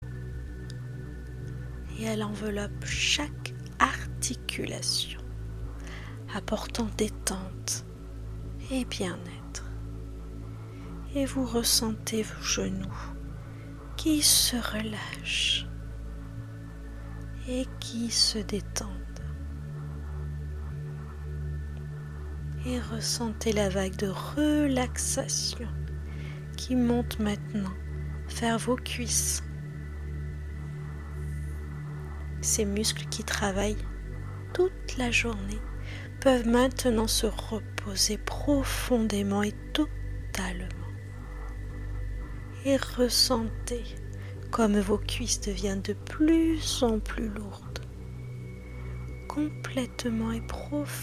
Voyage vers le Sommeil Profond est une séance d’hypnose thérapeutique spécialement conçue pour les adultes souffrant de troubles du sommeil.
• Composition sonore : Voix guidante + fond musical relaxant